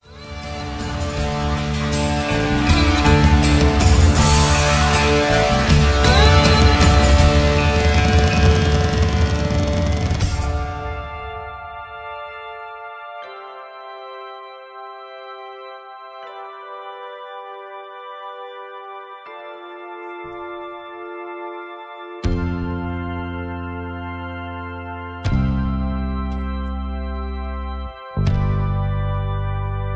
Karaoke Mp3 Backing Tracks
karaoke